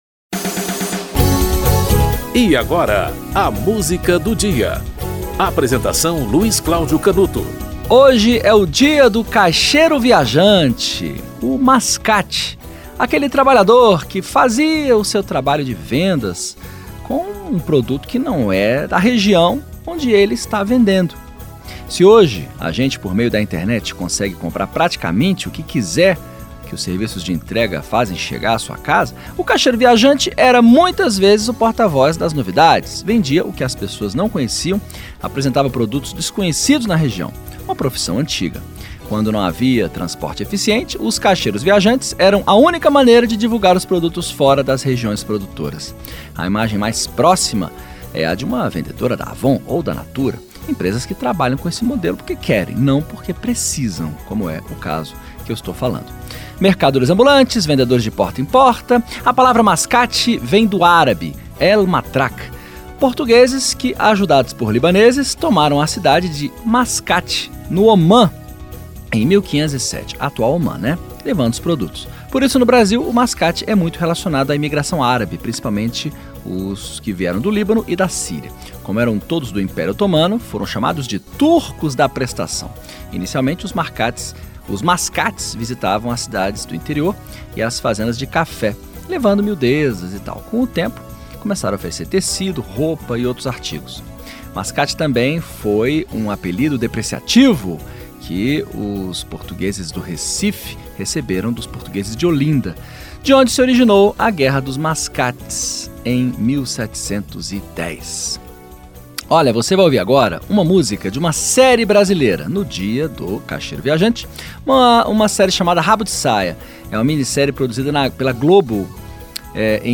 Produção e apresentação: